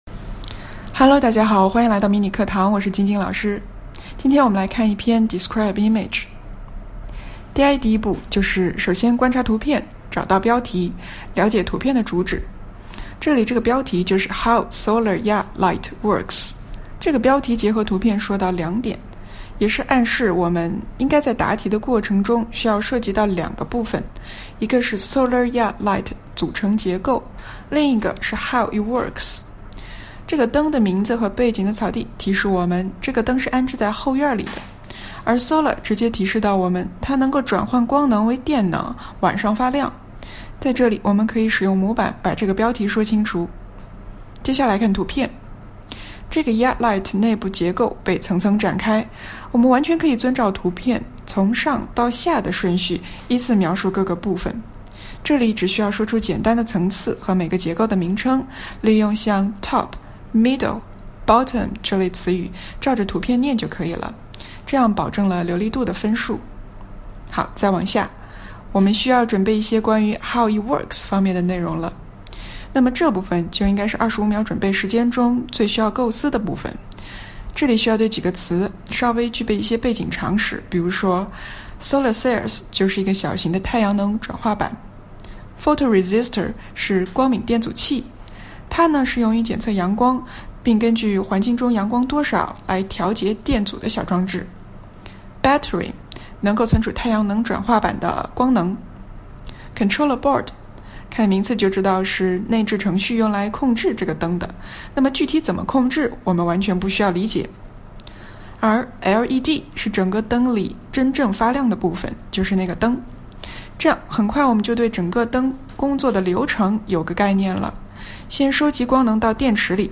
第二步，听老师讲解，比较出自己描述、发音、语调、断句等有问题的地方
DI-Sloar Yard Light 老师讲解.mp3